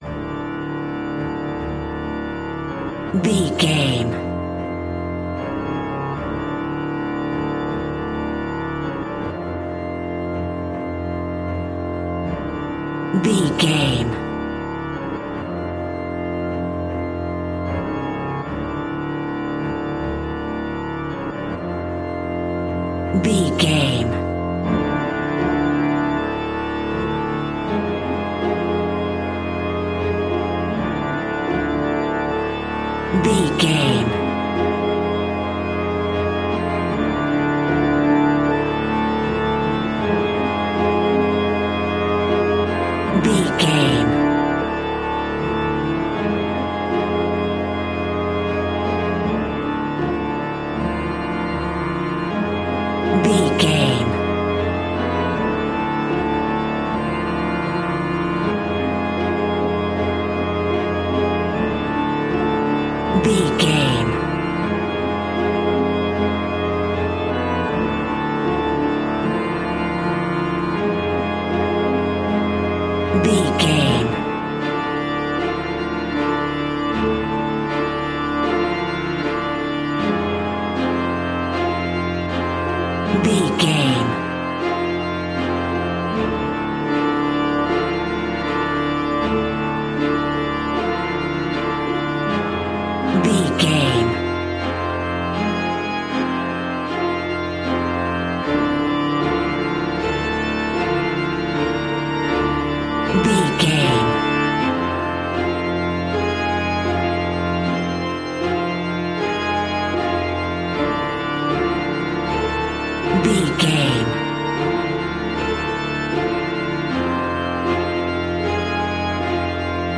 Creepy Funfair Organ.
In-crescendo
Aeolian/Minor
ominous
haunting
eerie